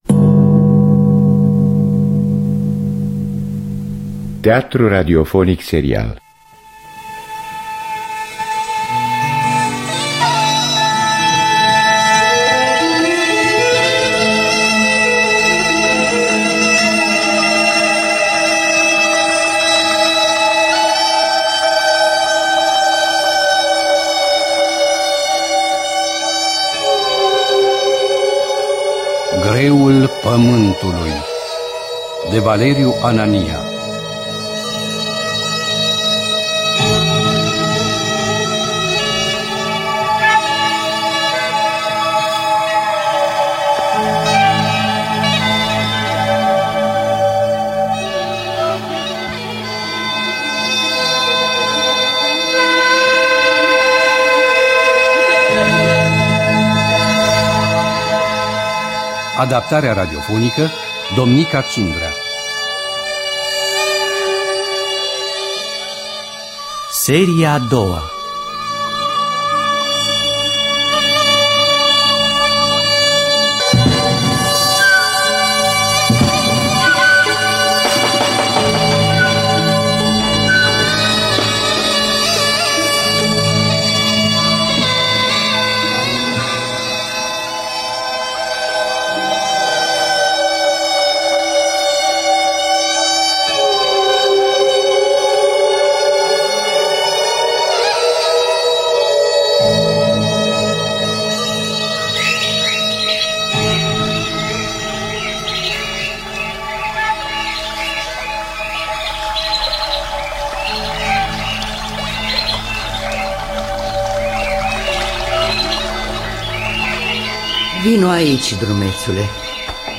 Valeriu Anania – Greul Pamantului (2002) – Episodul 2 – Teatru Radiofonic Online